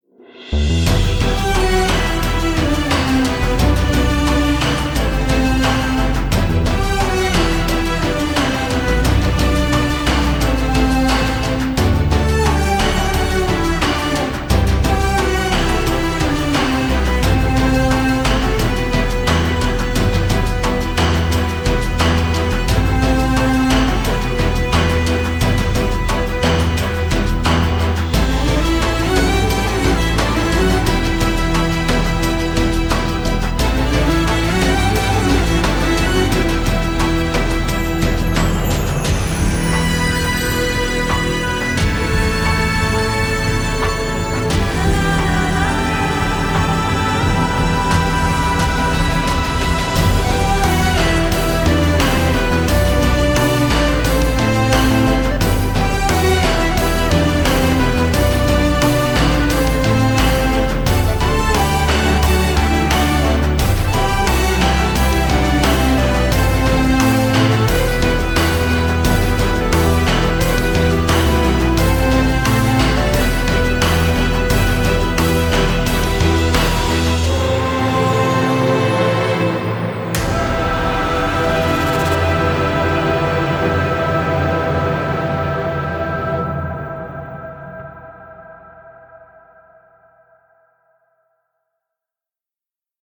duygusal hüzünlü rahatlatıcı fon müziği.